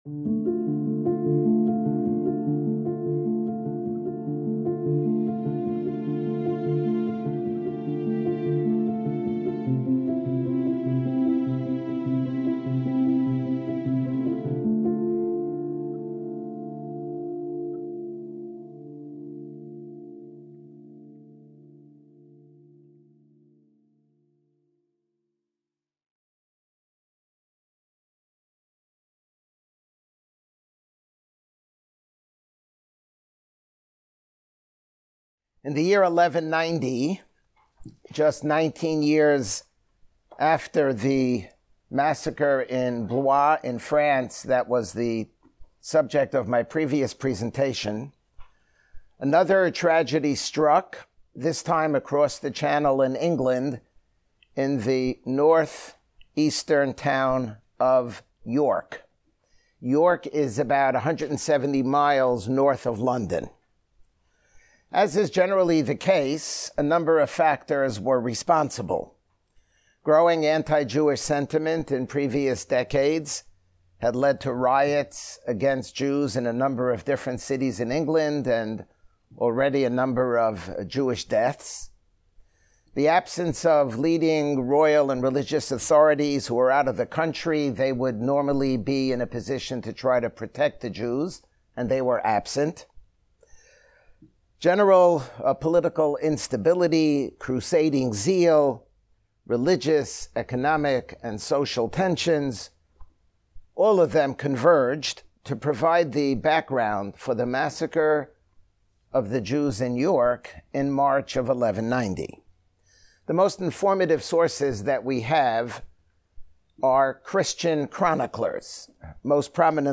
In this lecture